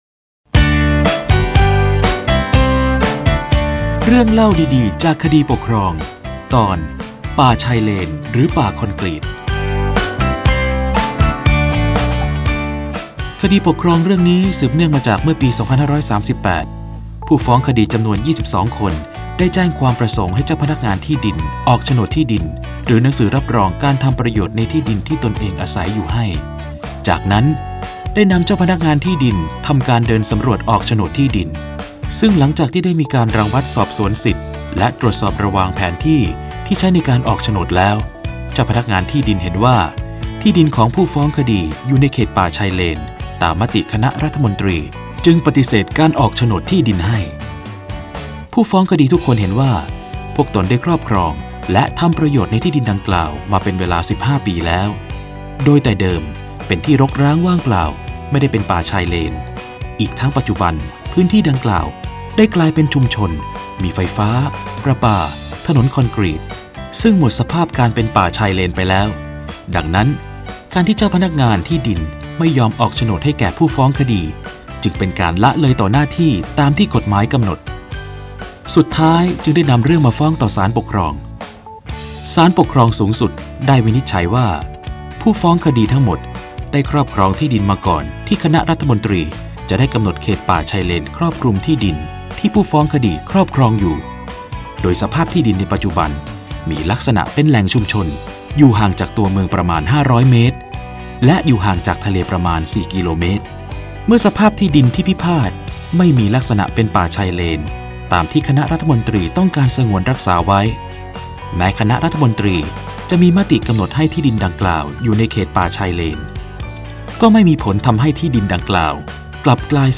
สารคดีวิทยุ ชุด เรื่องเล่าดีดีจากคดีปกครอง - ป่าชายเลนหรือป่าคอนกรีต